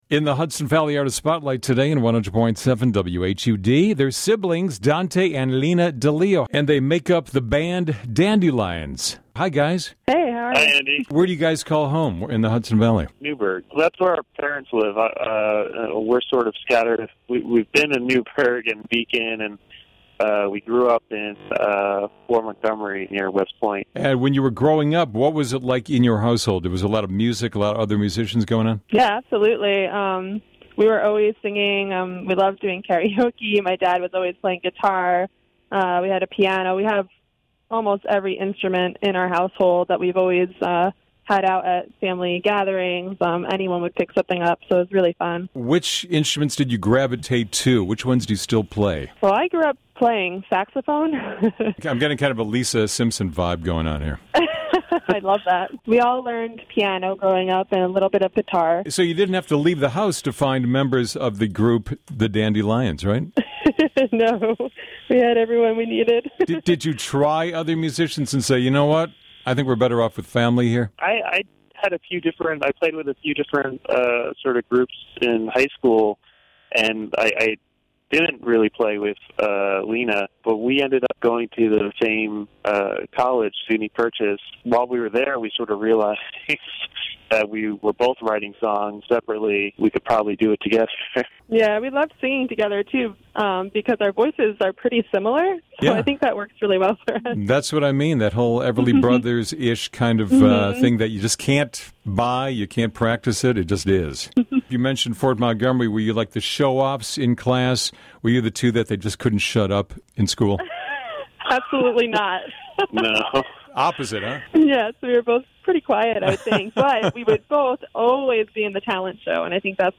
Interview with the dandy lions